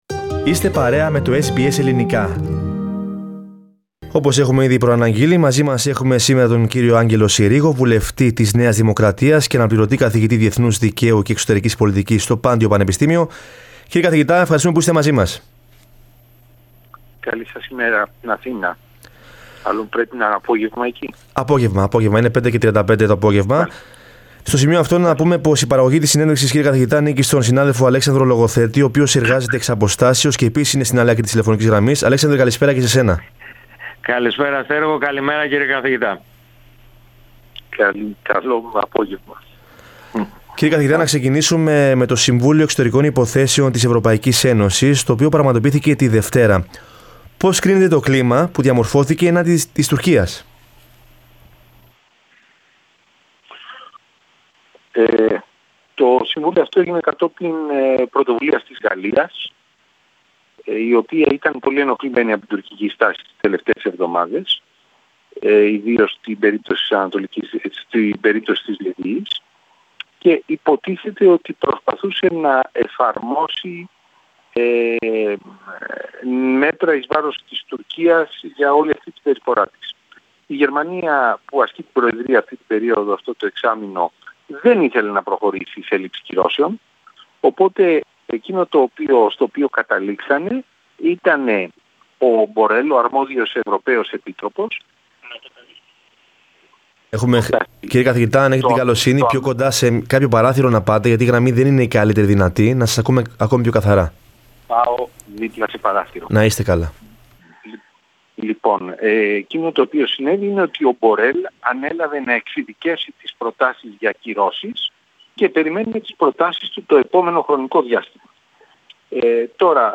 Η Ευρωπαϊκή Ένωση δεν είναι έτοιμη να επιβάλλει αυστηρές κυρώσεις στην Τουρκία, εκτιμά ο βουλευτής της ΝΔ και αναπληρωτής καθηγητής Διεθνούς Δικαίου και Εξωτερικής Πολιτικής, Άγγελος Συρίγος.
Σε συνέντευξή του στο SBS Greek, ο κ. Συρίγος μίλησε εκτενώς για τη στάση των Ευρωπαίων εταίρων απέναντι στην επιθετική τουρκική πολιτική.